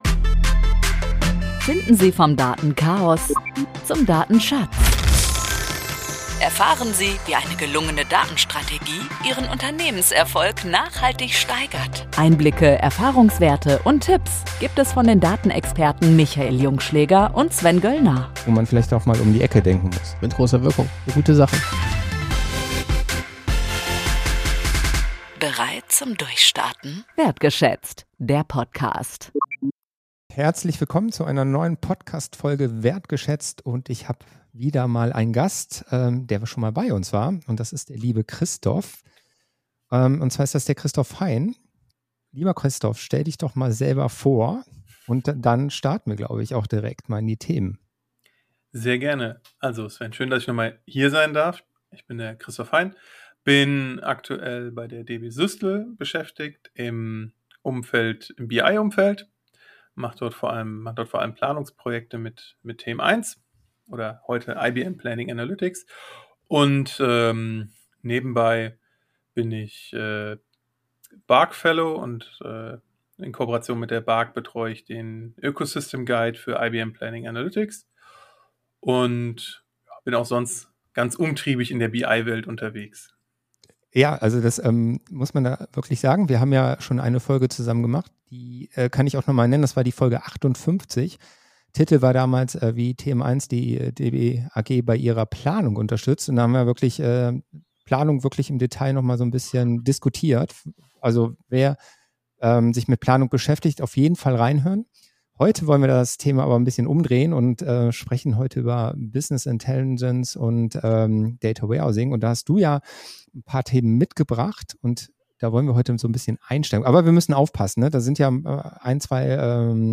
Eine hitzige Diskussion zwischen den beiden.